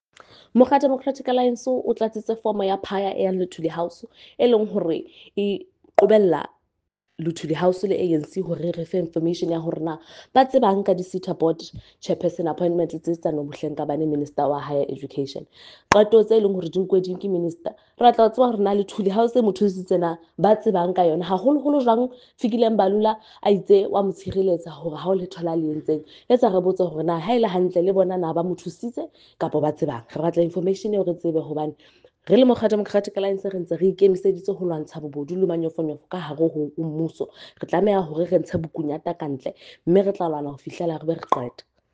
isiZulu soundbites by Karabo Khakhau MP.
Karabo-Sesotho-soundbite.mp3